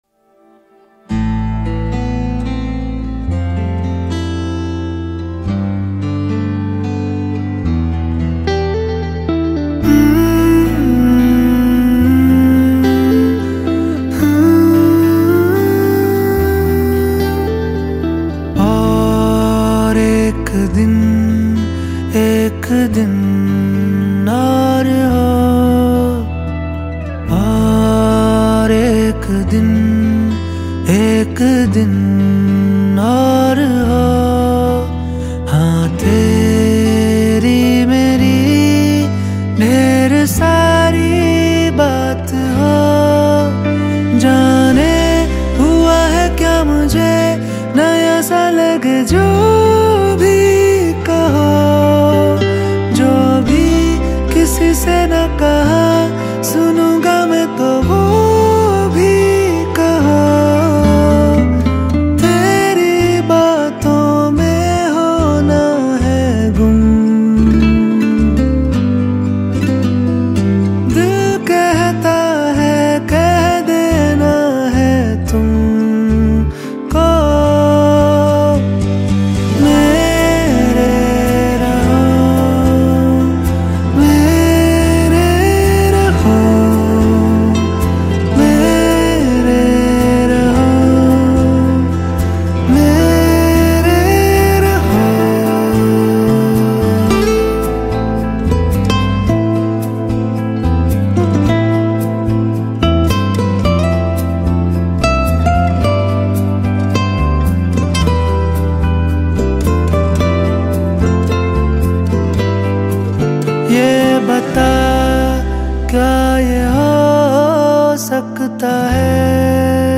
smooth and engaging tune